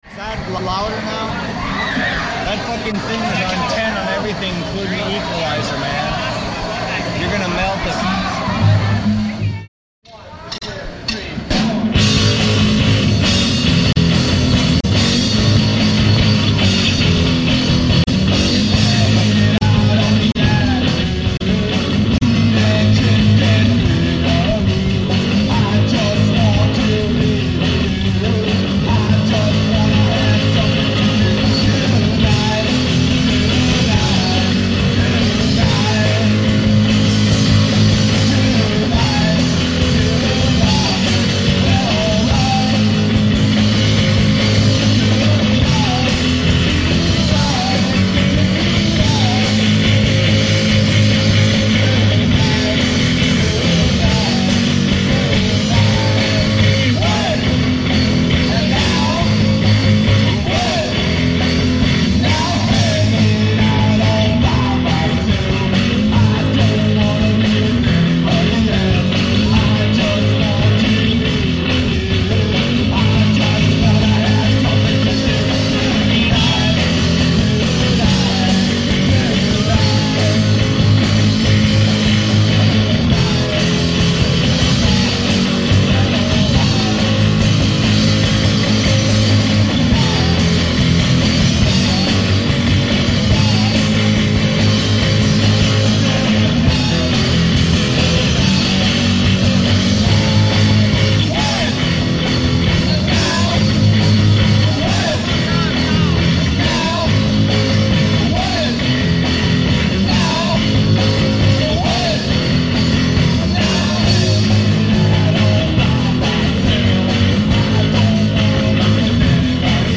From the MSHS Wind Ensemble Benefit in the High School Gym.